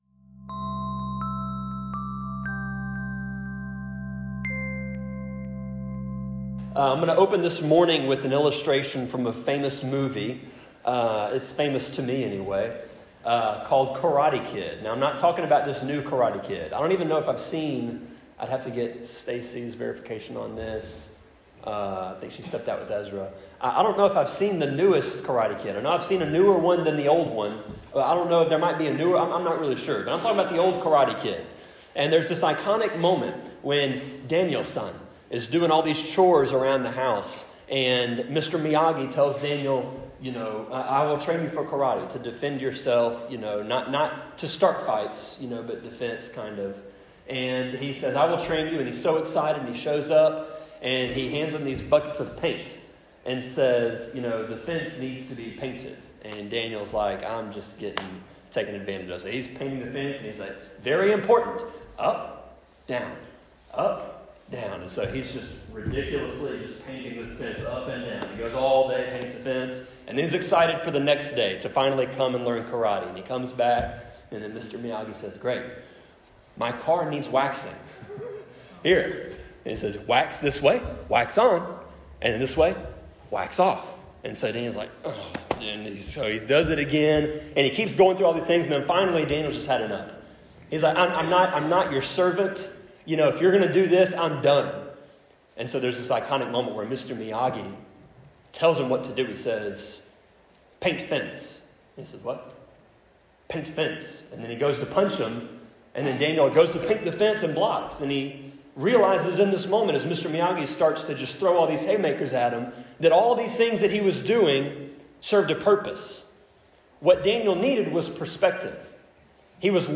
Sermon-25.1.12-CD.mp3